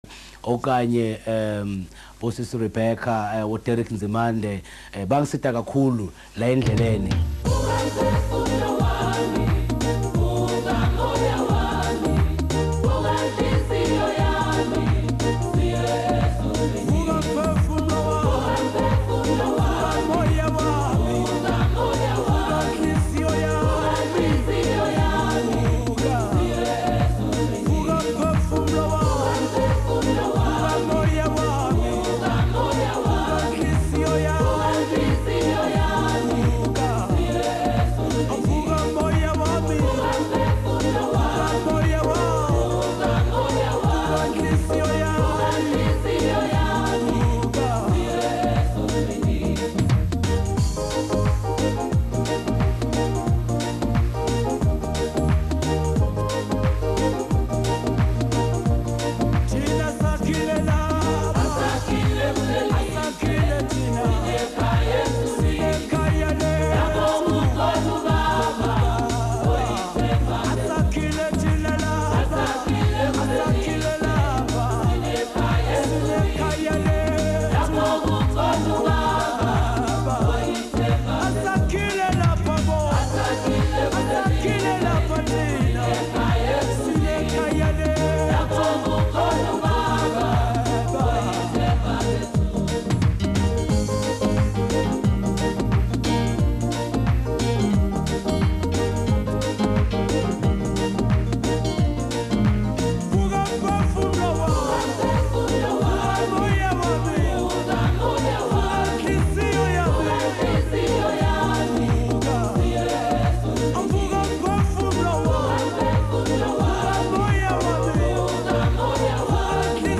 A Song of Spiritual Awakening